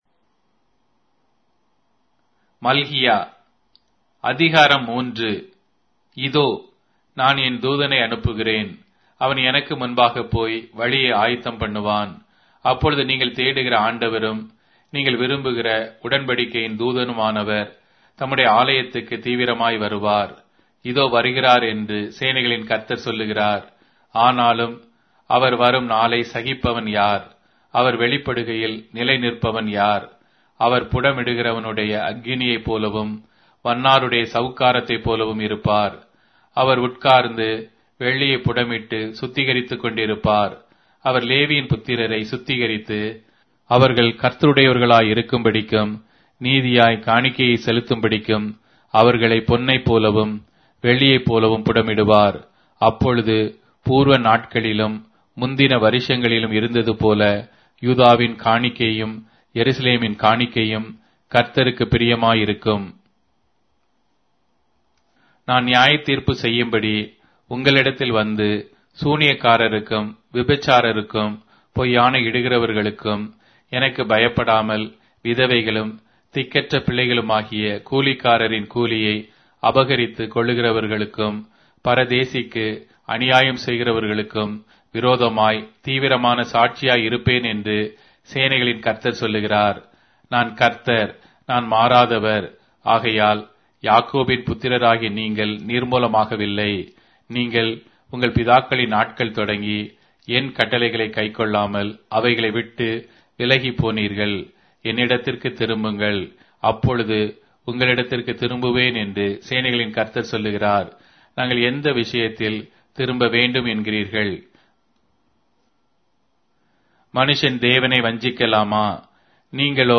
Tamil Audio Bible - Malachi 1 in Irvbn bible version